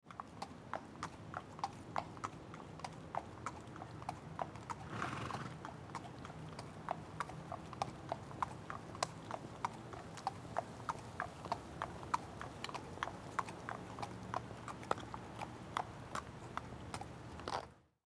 Horse Walk Slow Bouton sonore